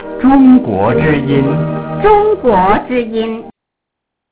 Clandestine Shortwave Stations Beamed to China
Transmitting from: Central Broadcasting System, Taiwan
Station ID: